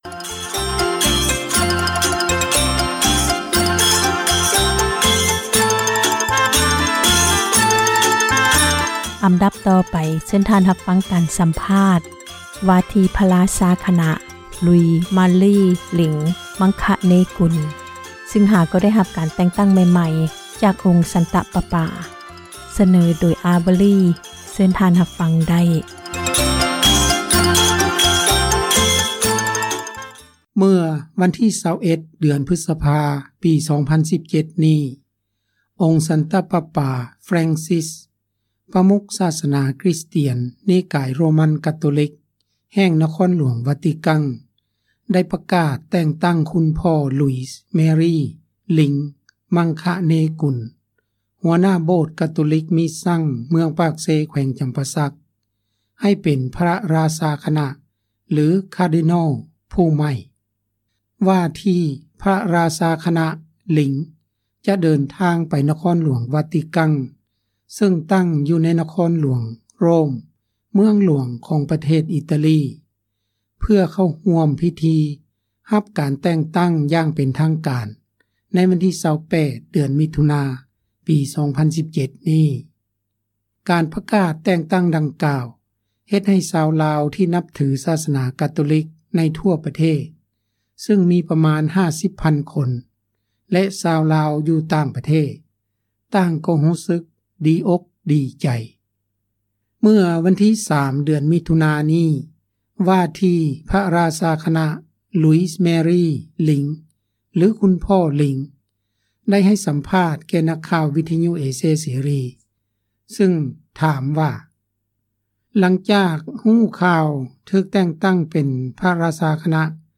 ການສຳພາດ ວ່າທີ່ ພຣະຣາຊາຄນະ ຫຼຸຍສ-ມາຣີ ຫຼິງ ມັງຄະເນກຸນ ຊຶ່ງຫາກໍໄດ້ ຮັບ ການແຕ່ງຕັ້ງໃໝ່ໆ ຈາກອົງສັນຕະປະປາ ເມື່ອທ້ານເດືອນ ທີ່ຜ່ານມາ.
ເມື່ອວັນທີ່ 3 ເດືອນມິຖຸນານີ້ ວ່າທີ່ພຣະຣາຊາຄະນະ ຫຼຸຍສ-ມາຣີ ຫຼິງ ຫຼືຄຸນພໍ່ ຫຼິງ ໄດ້ໃຫ້ສຳພາດ ແກ່ນັກຂ່າວ ວິທຍຸເອເຊັຍເສຣີ ຊຶ່ງຖາມວ່າ ຫລັງຈາກຮູ້ຂ່າວ ຖືກແຕ່ງຕັ້ງ ເປັນພຣະຣາຊາຄະນະ ຫຼື Cardinal ແລ້ວ ຄຸນພໍ່ຮູ້ສຶກ ແນວໃດ?